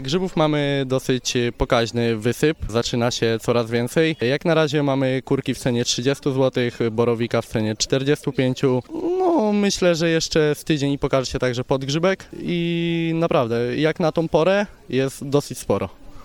Jeden ze sprzedawców grzybów powiedział nam parę słów o swoich produktach.